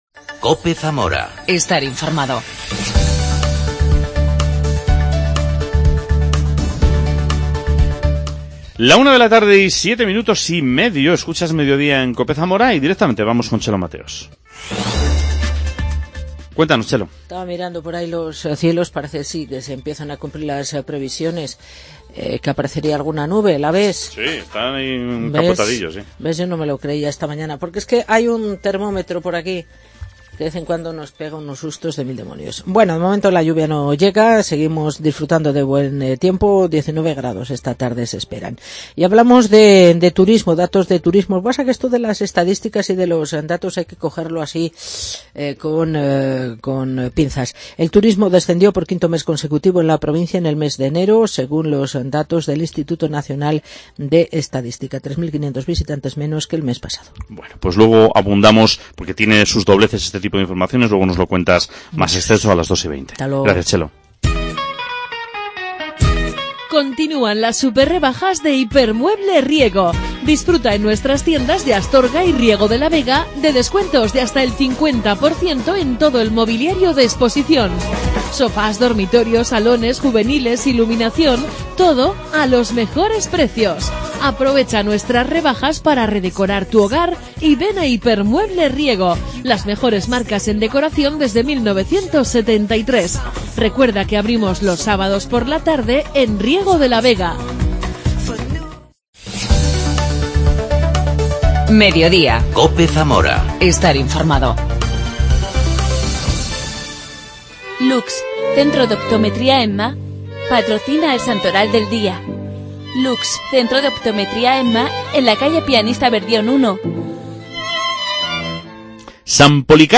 charla con vecinos de Vista Alegre sobre el mercadillo de los martes.